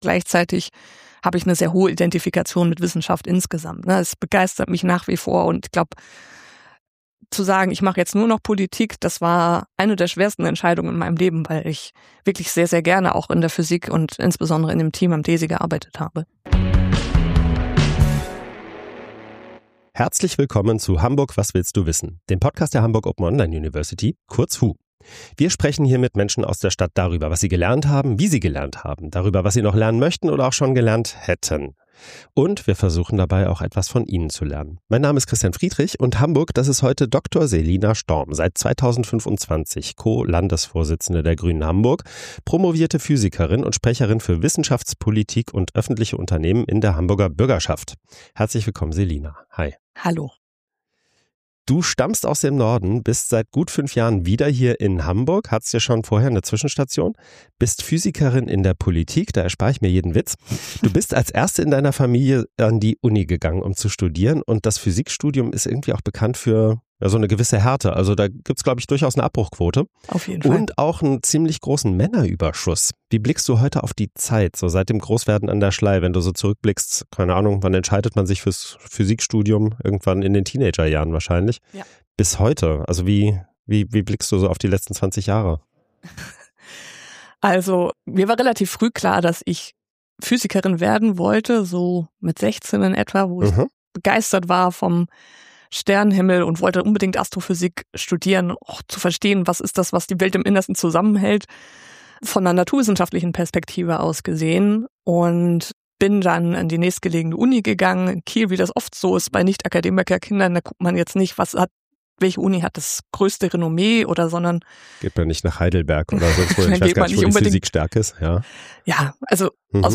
Hamburg, das ist heute: Selina Storm. Seit Sommer 2025 ist sie Mitglied der Hamburger Bürgerschaft und Co-Landesvorsitzende der GRÜNEN in Hamburg.